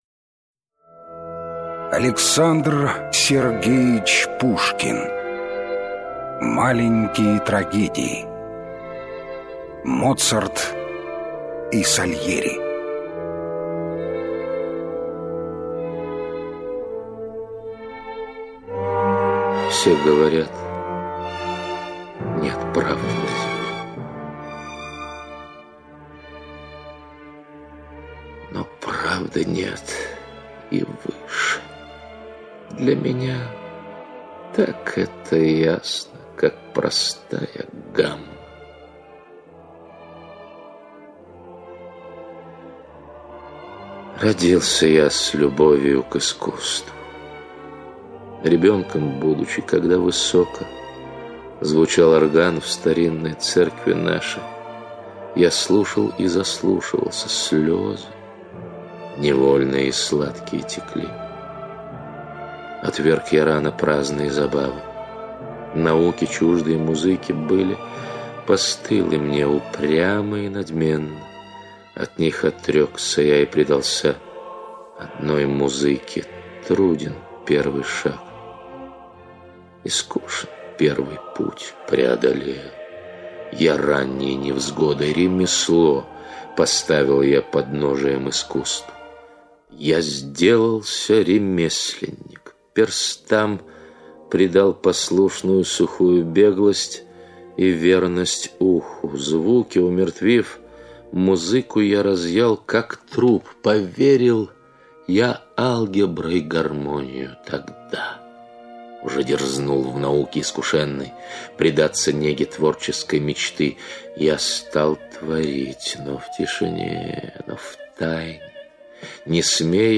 Аудиокнига Моцарт и Сальери
Качество озвучивания весьма высокое.